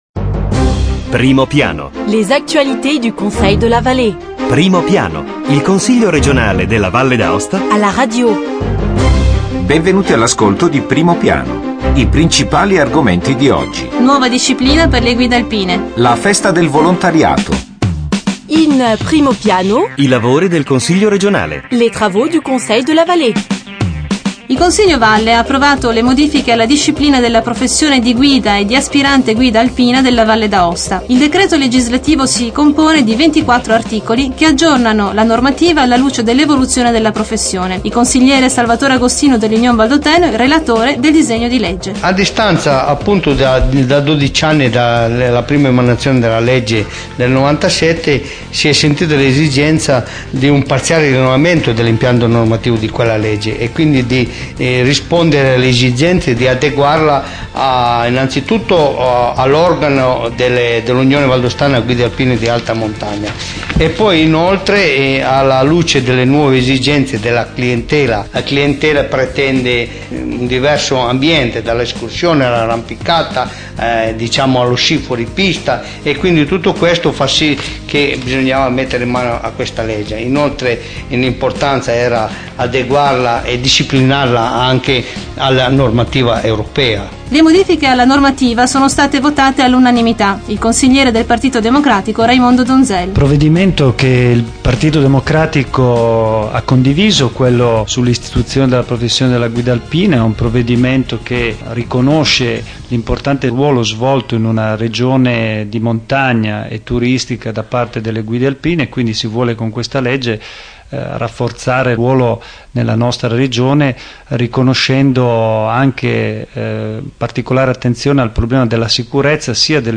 Eventi e ricorrenze Documenti allegati Dal 13 ottobre 2009 al 20 ottobre 2009 Primo Piano Il Consiglio regionale alla radio: approfondimento settimanale sull'attivit� politica, istituzionale e culturale dell'Assemblea legislativa.
Intervista ai Consiglieri Salvatore Agostino e Raimondo Donzel.